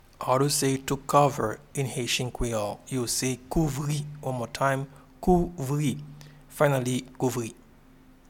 Pronunciation and Transcript:
To-cover-in-Haitian-Creole-Kouvri.mp3